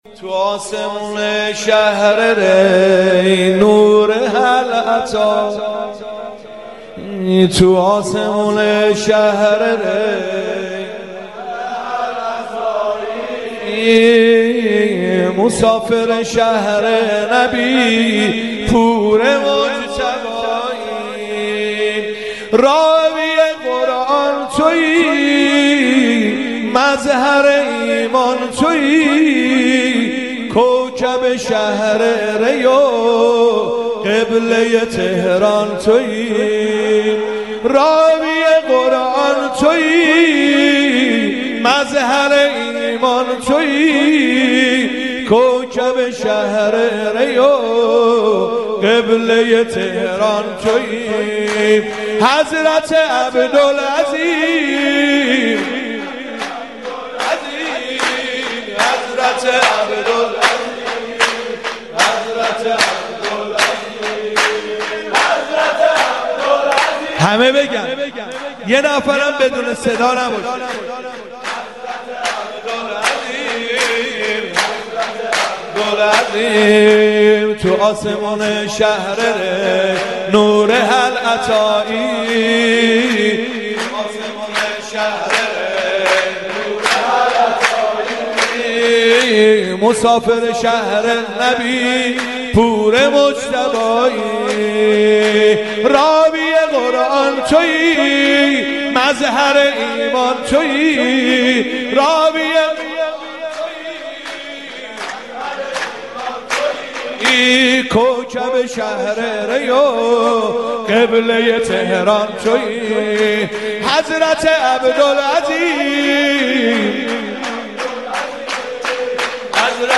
گلچین مداحی های میلاد حضرت عبدالعظیم(ع)